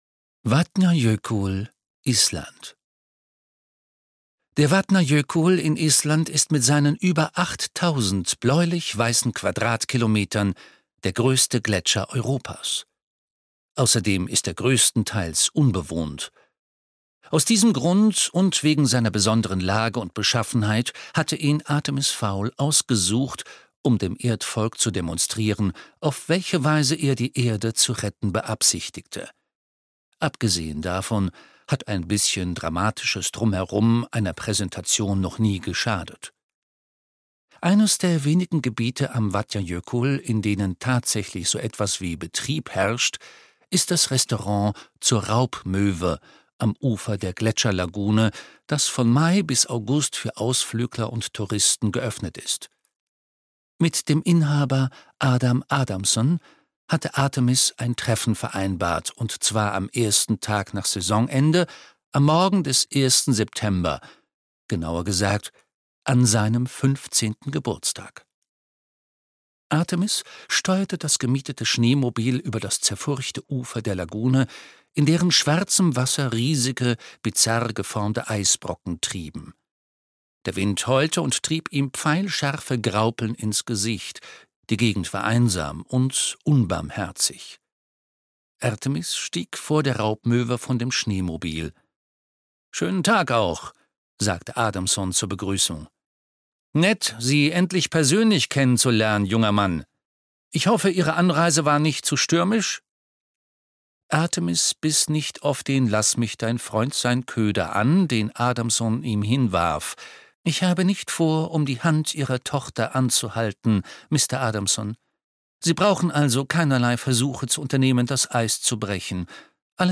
Artemis Fowl - Der Atlantis-Komplex (Ein Artemis-Fowl-Roman 7) - Eoin Colfer - Hörbuch